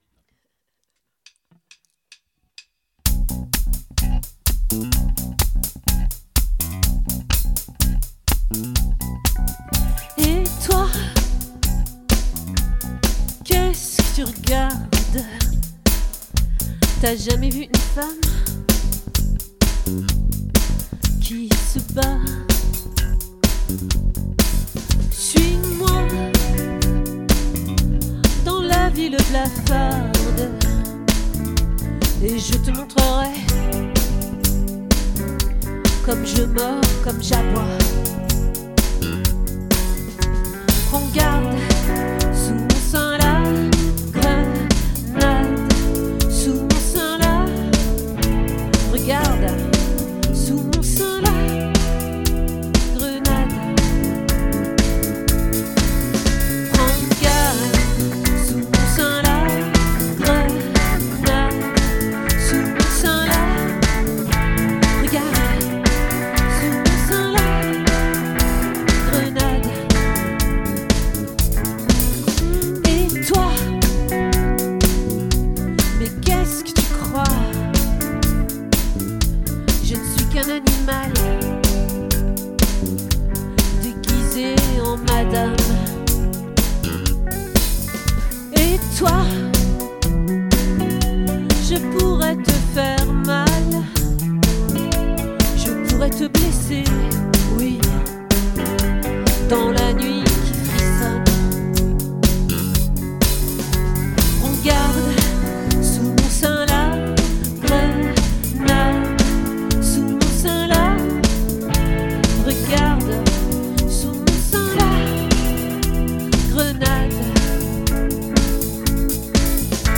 🏠 Accueil Repetitions Records_2024_08_29